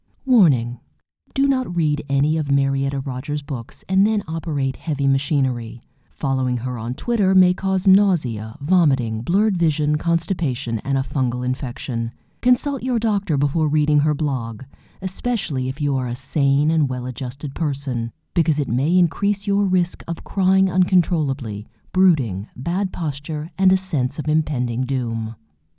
The following is an important public service announcement.
1. Awesome PSA!